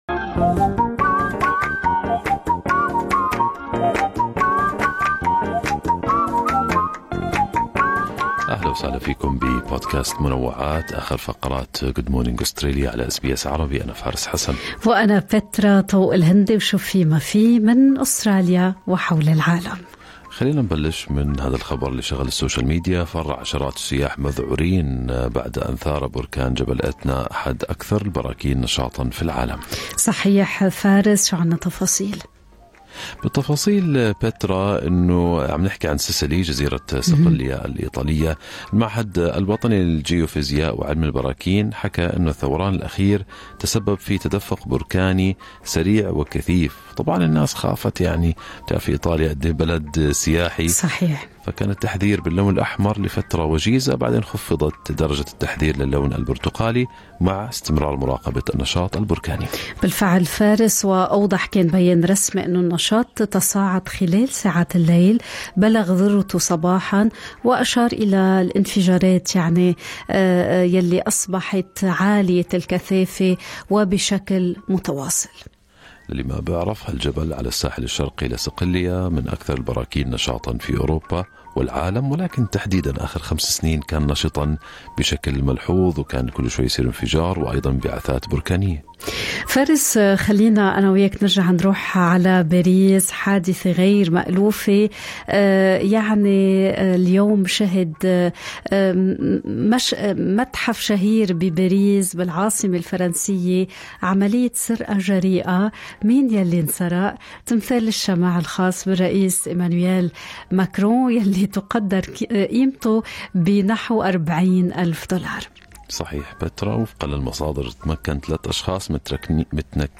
نقدم لكم فقرة المنوعات من برنامج Good Morning Australia التي تحمل إليكم بعض الأخبار والمواضيع الأكثر رواجاً على مواقع التواصل الاجتماعي.